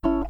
Custom notification sounds
Because they were meant to be played on phones alone, I was not too concerned with audio quality besides hopefully avoiding weird artifacts or unwanted high-ish pitch noise that are especially noticable through a phone's tiny speaker(s).
These were recorded ad hoc after work, using my first ukulele (miguel almeria pure series, concert) with low-g tuning with having a "sometimes quiet office environment" in mind (so that it's audible, while also not being too abrasive to distract others).